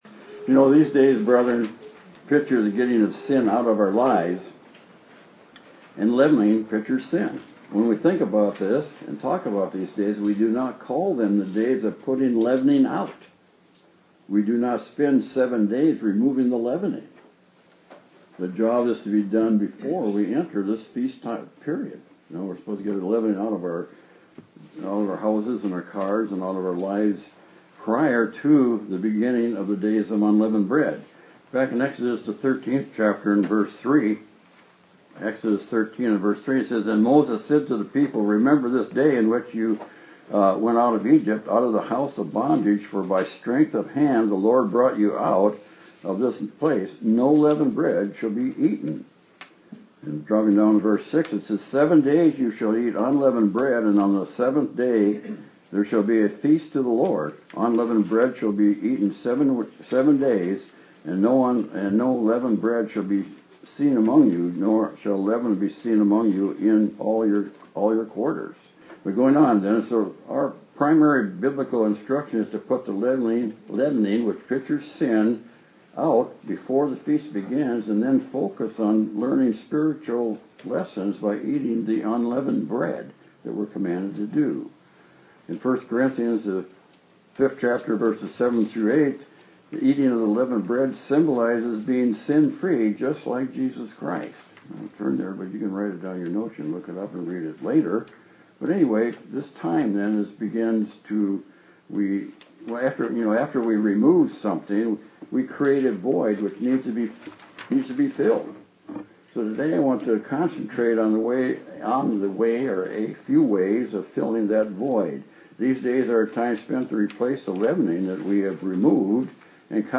Sermons
Given in Austin, TX